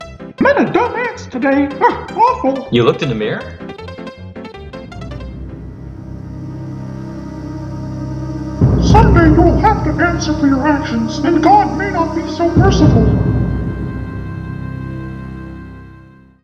Tag: voice acting